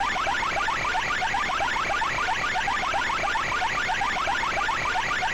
shard_expel.ogg